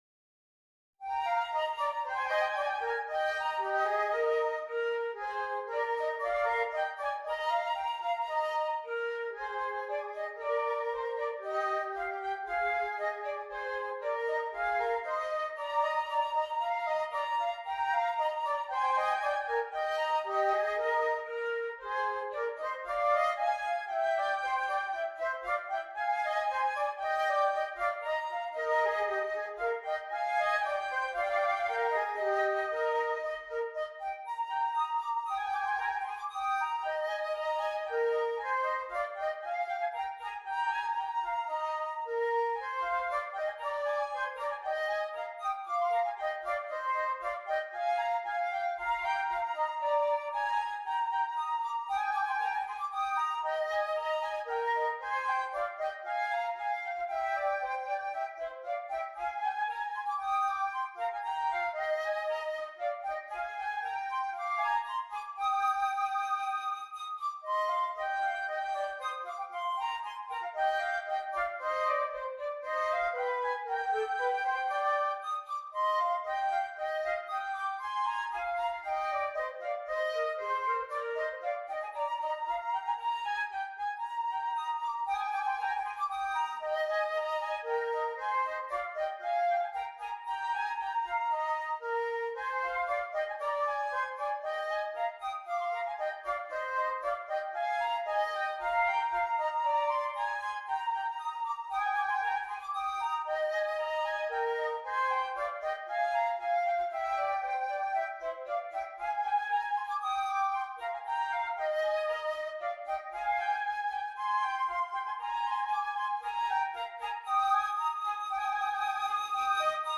2 Flutes
duet